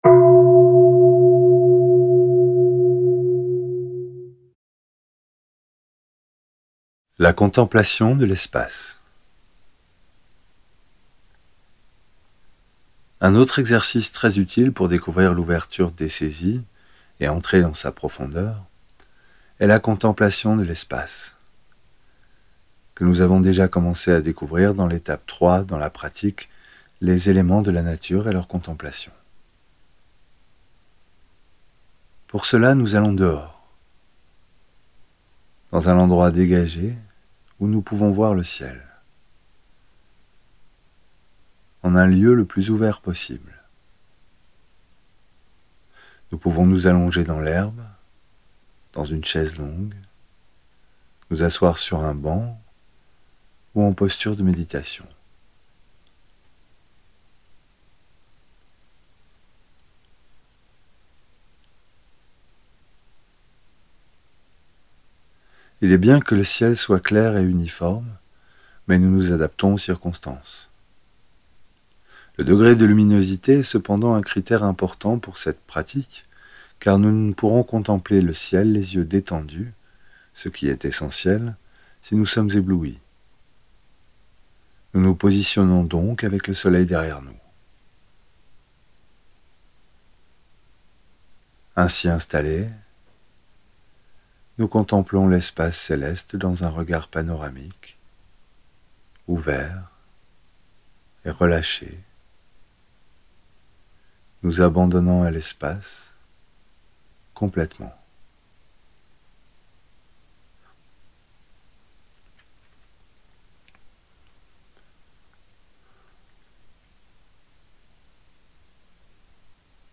Audio homme
8.ETAPE-4-AUDIO-9-P27-HOMME.mp3